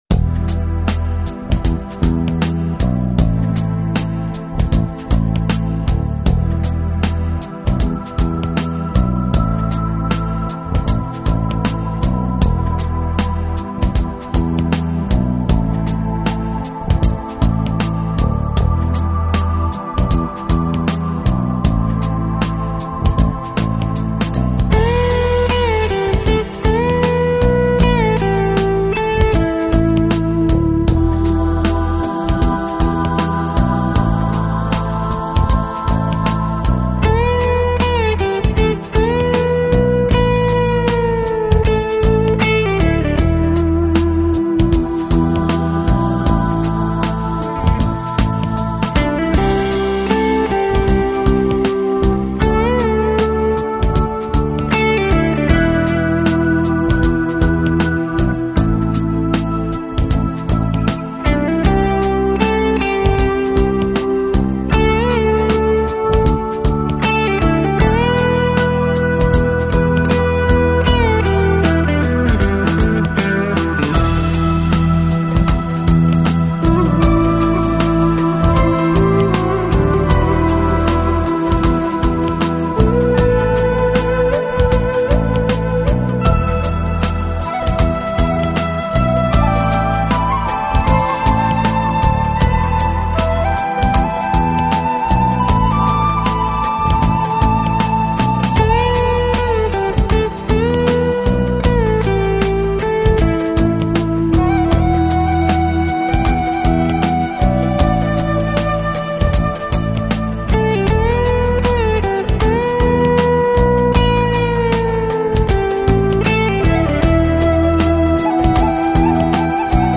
音乐风格：New Age
迷人的声音体现国际风味。